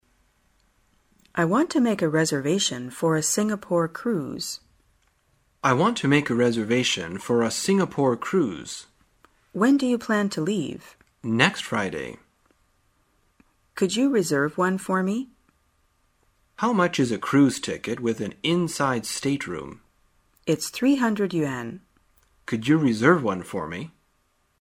在线英语听力室生活口语天天说 第128期:怎样预订船票的听力文件下载,《生活口语天天说》栏目将日常生活中最常用到的口语句型进行收集和重点讲解。真人发音配字幕帮助英语爱好者们练习听力并进行口语跟读。